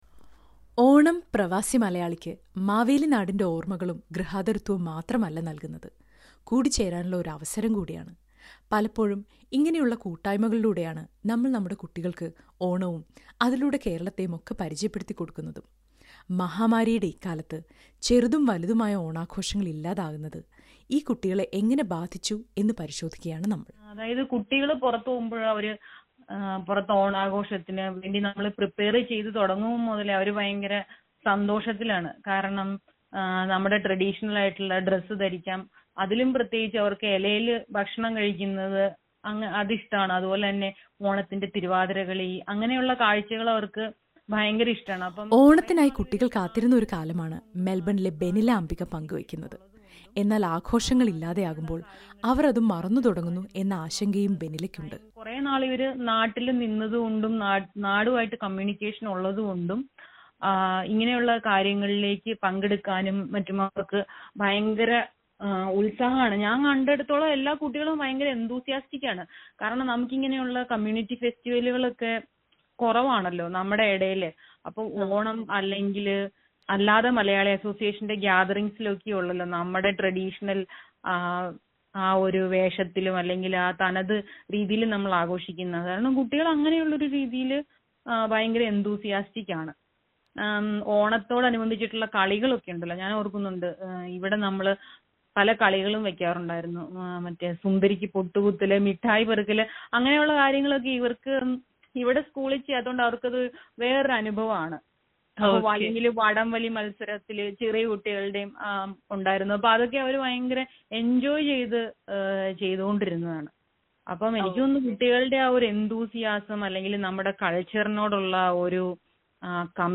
Yet another Onam without the usual celebrations. Parents and children share how badly they miss the good old days when they could have all their friends and family around for a colourful day of celebrations. Listen to a report.